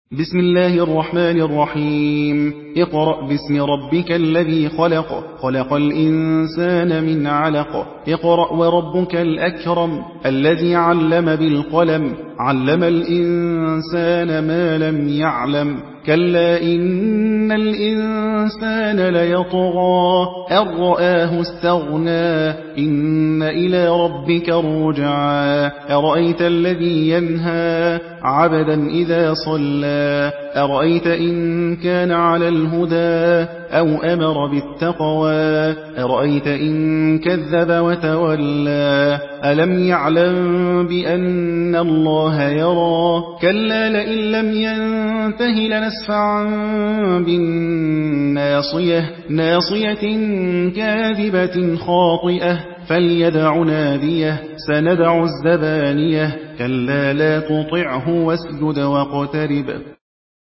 حدر